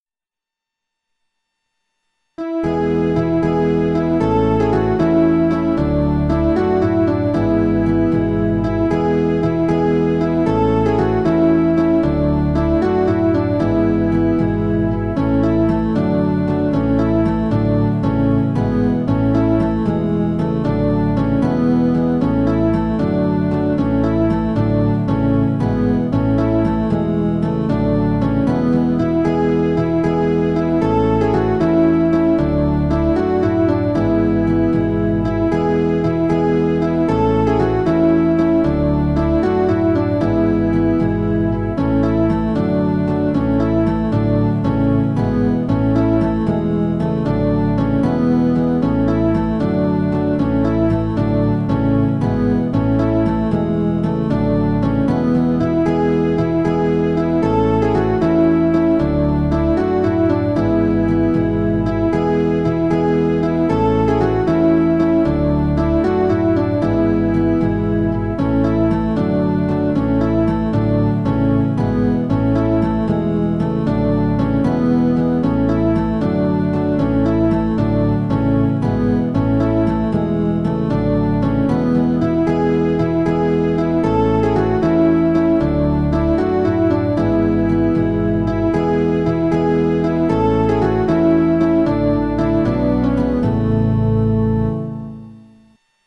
A joyous Praise and Worship to our God of Salvation.